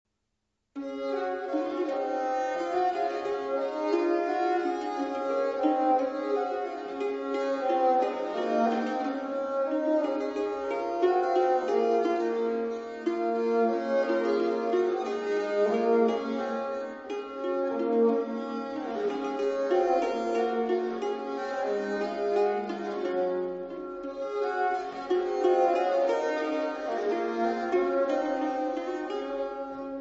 • mottetti
• Motet